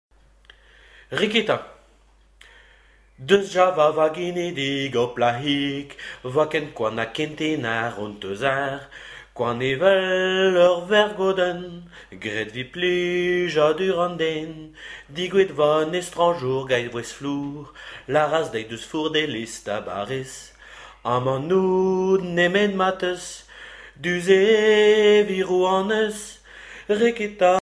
Genre strophique
Témoignages et chansons